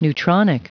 Prononciation du mot neutronic en anglais (fichier audio)
Prononciation du mot : neutronic